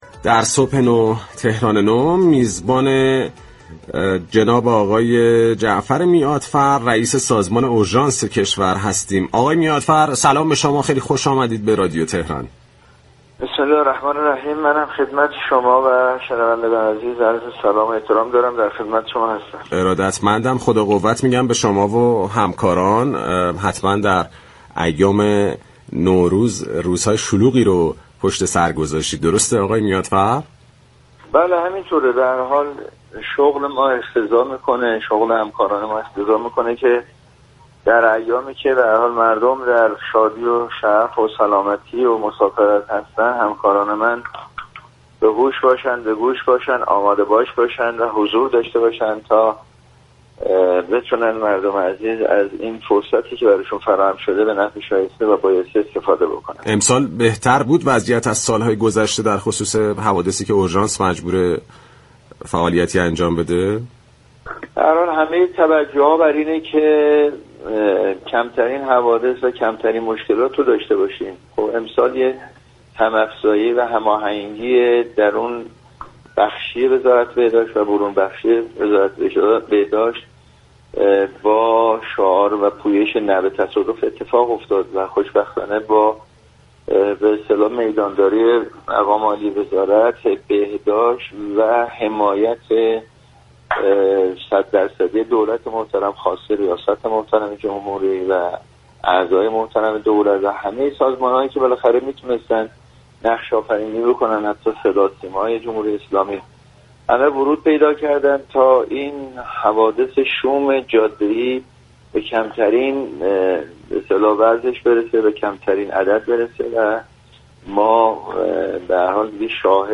به گزارش پایگاه اطلاع رسانی رادیو تهران، جعفر میعادفر كه با برنامه "صبح نو، تهران نو" همراه بود، با اشاره به تلاش های اورژانس كشور در ایام تعطیلات نوروز اظهار كرد: شاهد هم افزایی و هماهنگی داخلی و خارجی وزارت بهداشت در راستای پویش نه به تصادف بودیم.